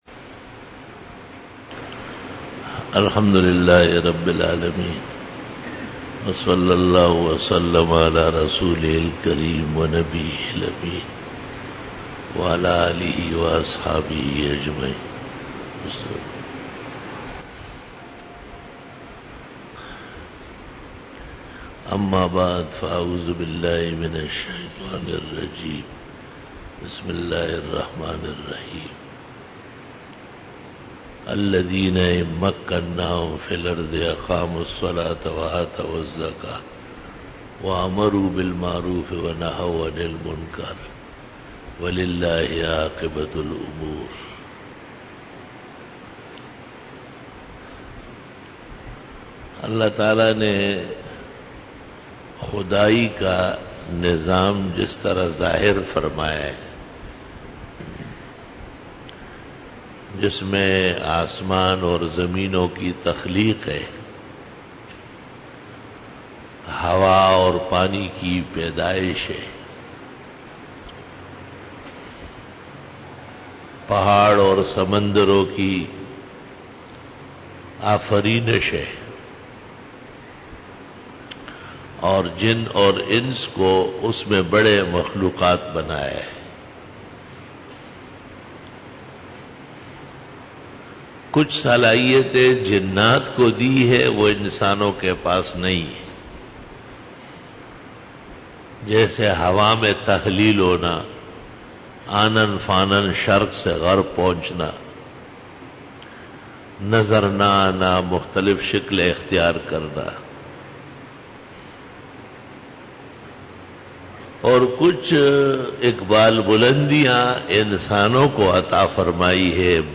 Khitab-e-Jummah 2013